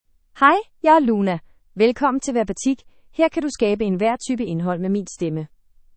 Luna — Female Danish AI voice
Luna is a female AI voice for Danish (Denmark).
Voice sample
Listen to Luna's female Danish voice.
Luna delivers clear pronunciation with authentic Denmark Danish intonation, making your content sound professionally produced.